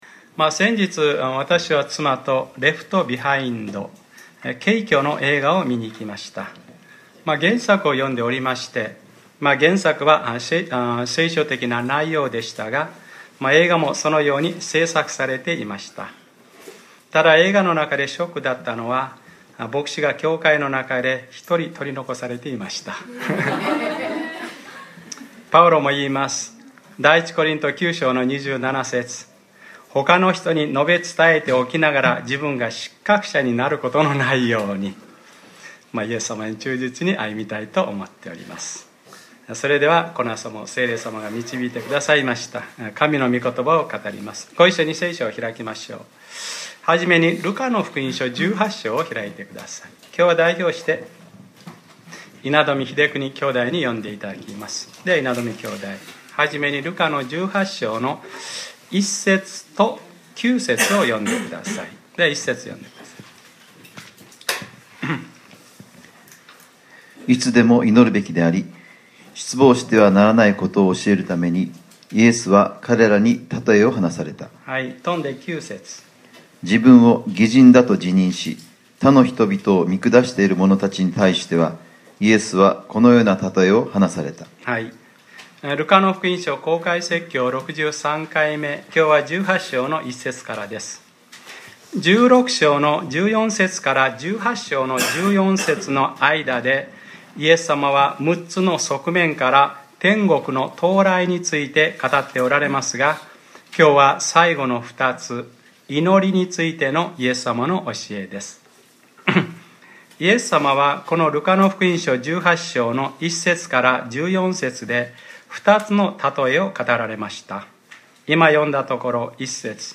2015年07月05日（日）礼拝説教 『ルカｰ６３：こんな罪びとの私をあわれんでください』 | クライストチャーチ久留米教会